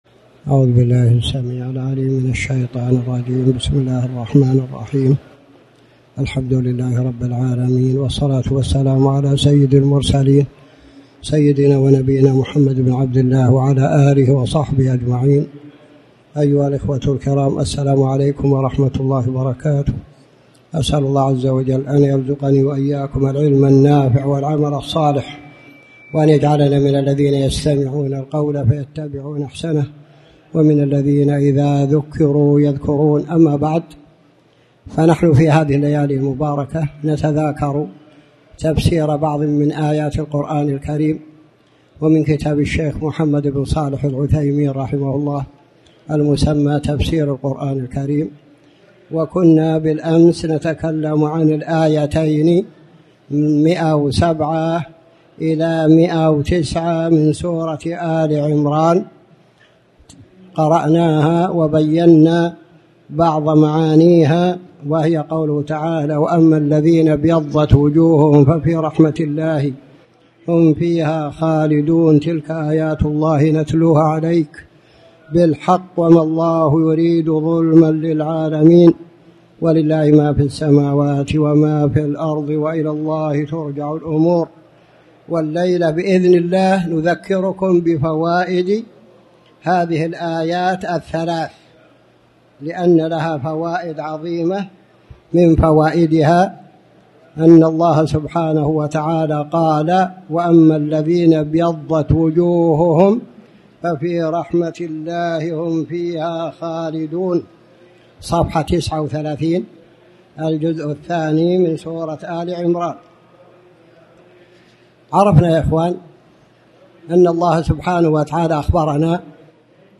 تاريخ النشر ٢٤ ربيع الأول ١٤٣٩ هـ المكان: المسجد الحرام الشيخ